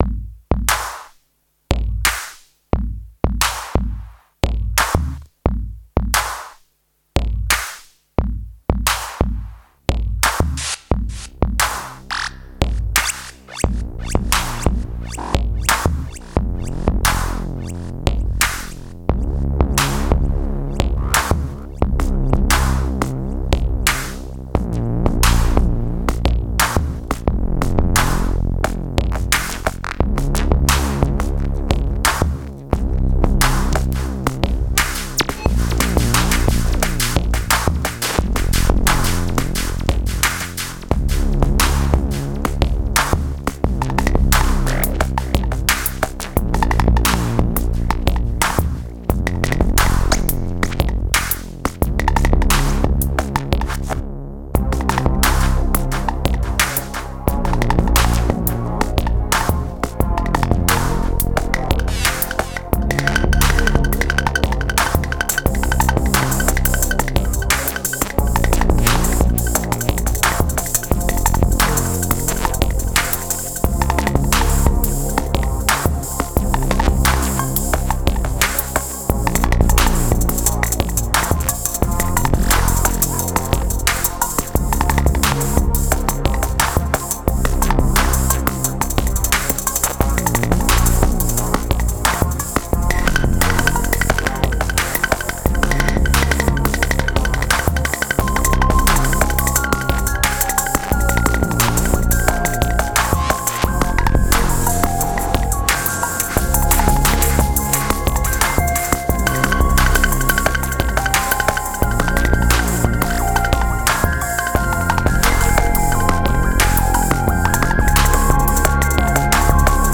Was just about to go to bed but decided to instead explore the temp ctrl all thing on the t1 and the analog rytm, p12 and rev2; the stems imported into bitwig for some valhalla effects. May have gone to hard on the ar compressor.